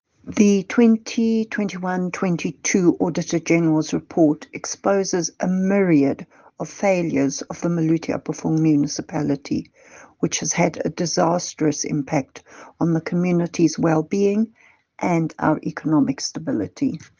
English and Afrikaans soundbites by Cllr Alison Oates and